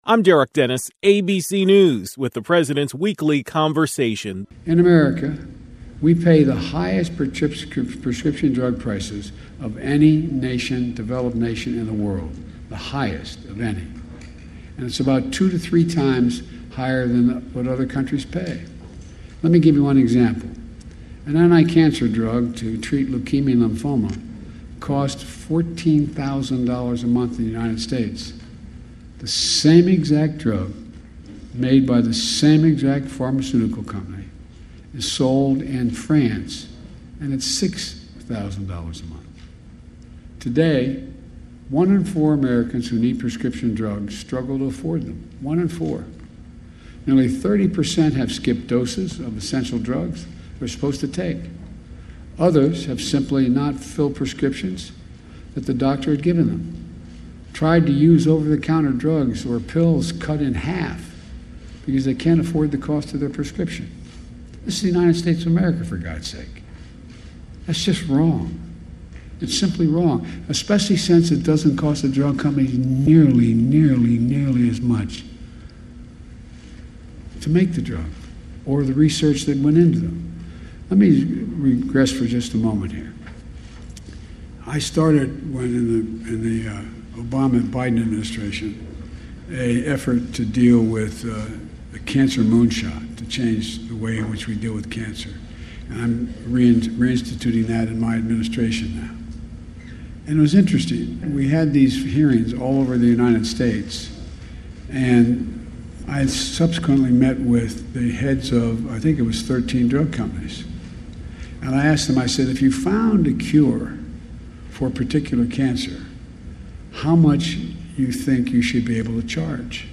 President Biden visited Germanna Community College in Culpeper, Virginia.